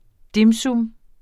Udtale [ ˈdimˈsum ] eller [ ˈdimˈsɔm ]